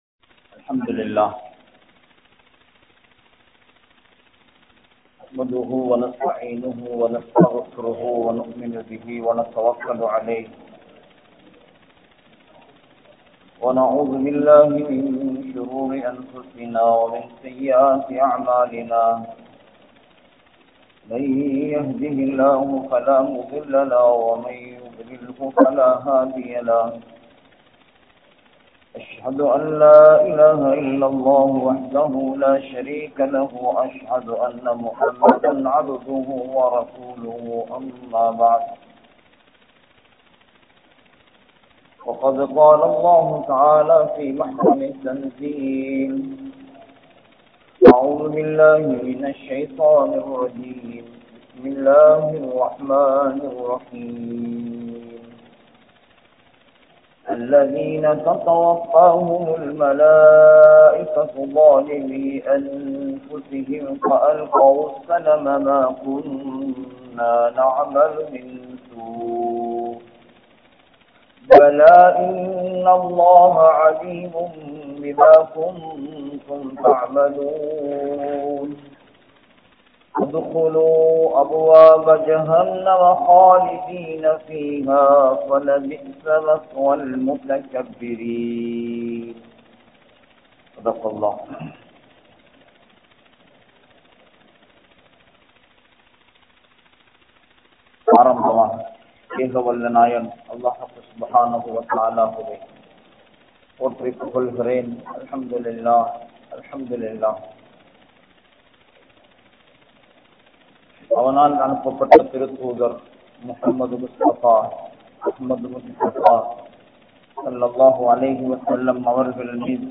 Allah`vukku Kattup Padungal(அல்லாஹ்வுக்கு கட்டுப்படுங்கள்) | Audio Bayans | All Ceylon Muslim Youth Community | Addalaichenai